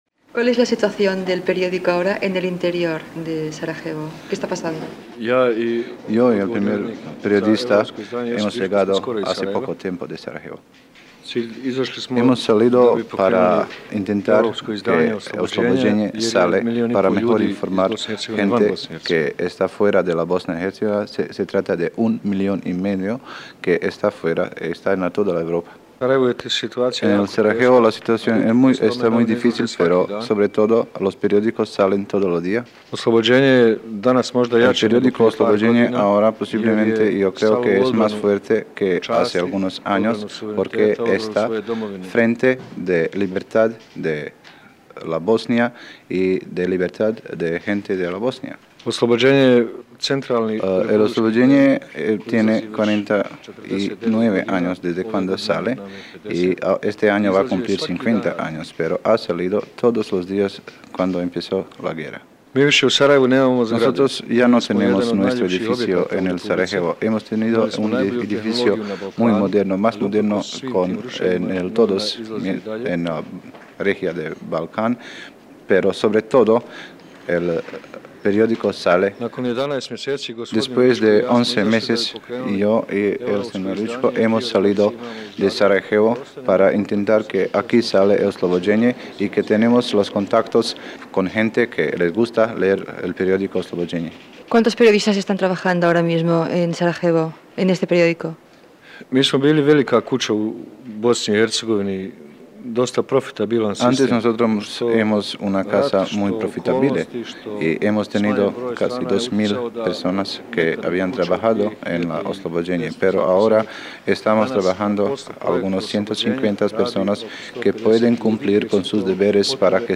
Reportatge fet a Sarajevo (Bòsnia i Hercegovina) sobre la situació de la premsa local i la dels joves croates i de Bòsnia Hercegovina